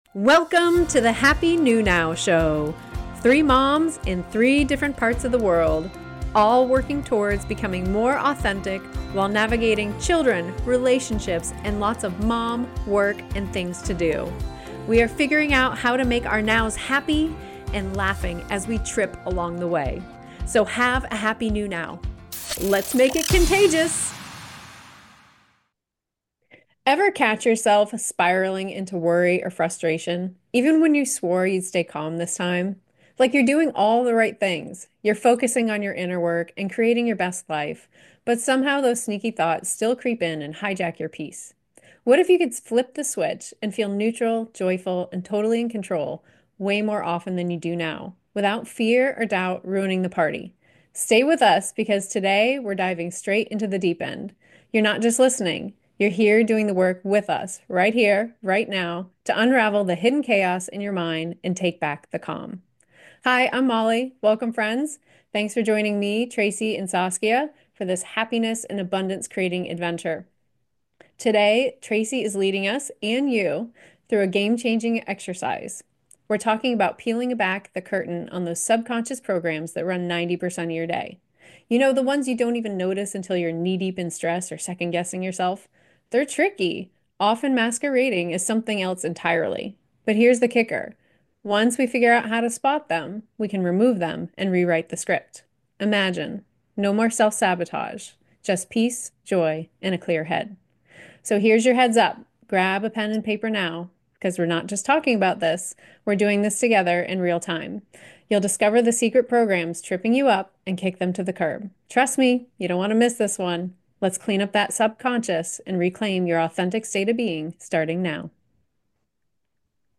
Talk Show Episode, Audio Podcast, Trans-Ending Mind Control: Don't Resolve...Dissolve!